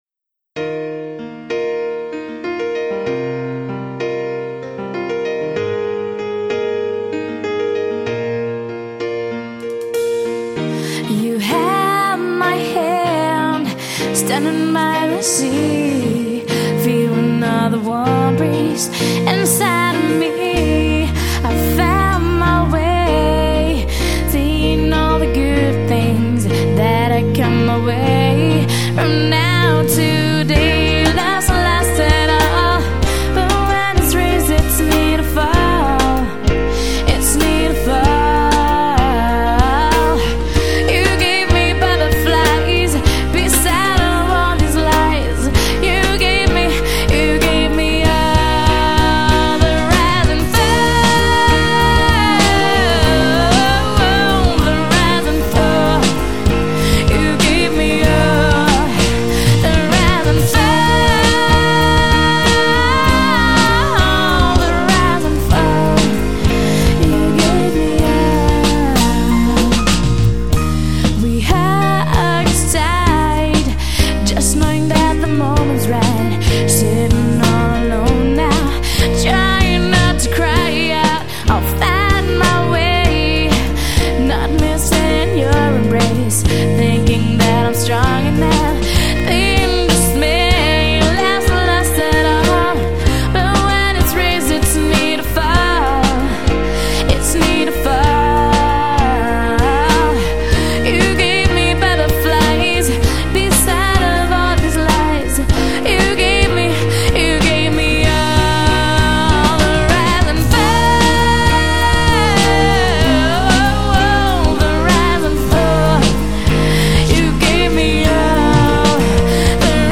Pop/Rock bis Soul
piano
drums